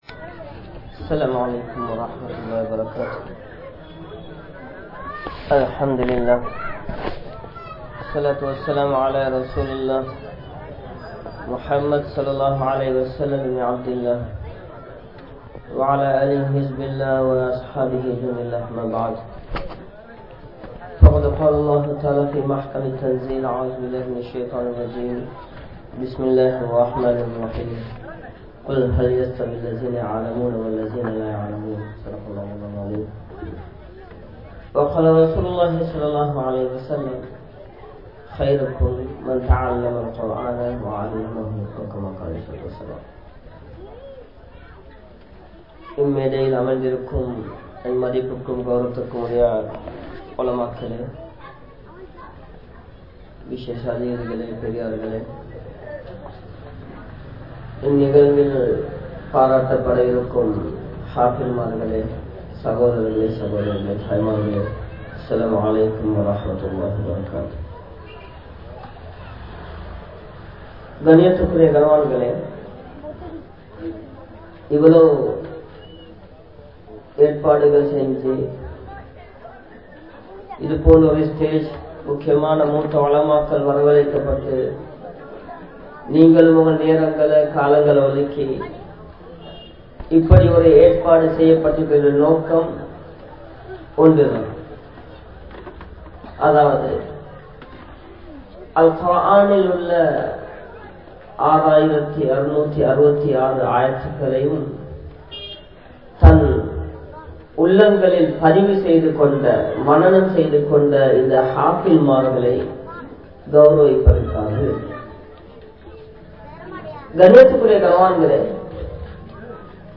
Al Quranai Sumantha Ullangal (அல்குர்ஆனை சுமந்த உள்ளங்கள்) | Audio Bayans | All Ceylon Muslim Youth Community | Addalaichenai